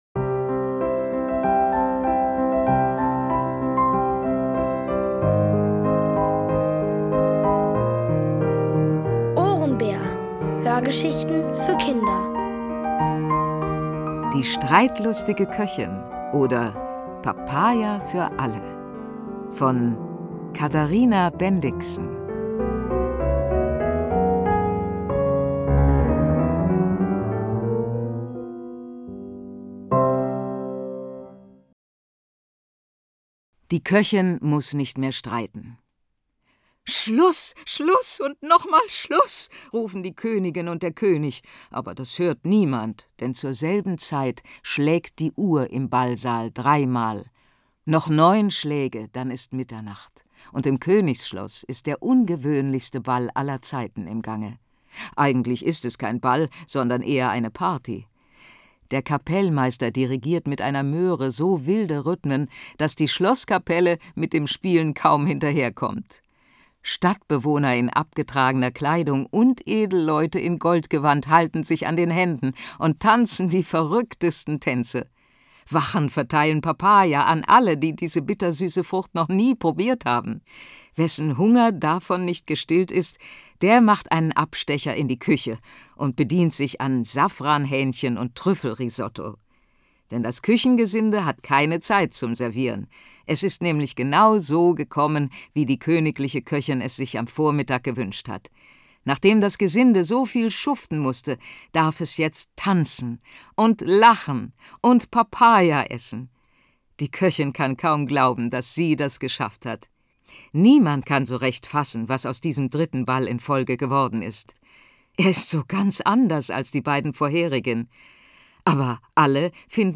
Die streitlustige Köchin hat alles auf den Kopf gestellt. Aber das finden jetzt alle gut. Nur das Königspaar muss umdenken. Dafür wird die Prinzessin neugierig auf den Kohlenmuffel. Aus der OHRENBÄR-Hörgeschichte: Die streitlustige Köchin oder: Papaya für alle! (Folge 6 von 6) von Katharina Bendixen. Es liest: Regina Lemnitz.